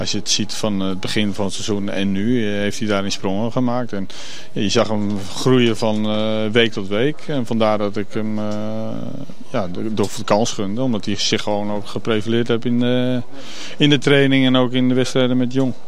Man of the Match was dus Viktor Fischer. Ajax-trainer Frank de Boer over het jonge talent: